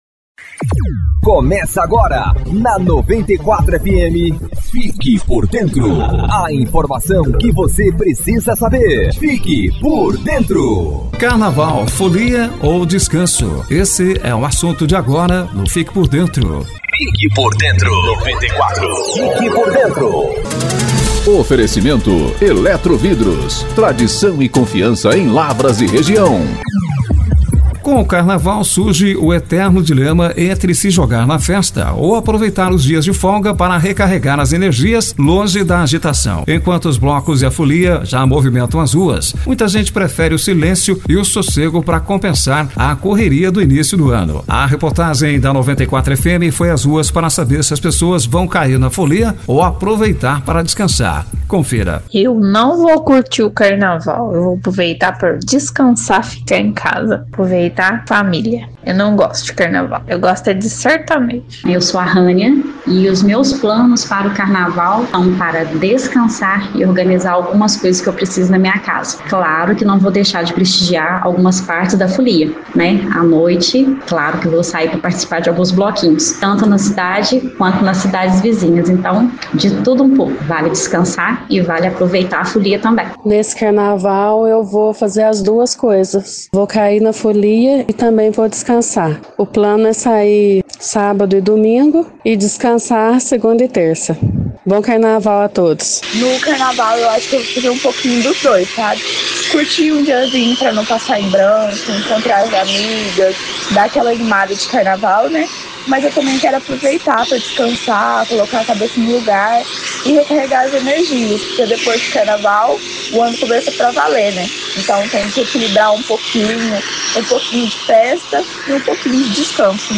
Enquanto os blocos já movimentam as ruas, uma reportagem da 94FM ouviu a população para entender as preferências deste ano. Os relatos mostram um cenário dividido: há quem não abra mão da tradição carnavalesca, mas também um número crescente de pessoas que priorizam o silêncio e o convívio familiar para compensar a correria do início de ano.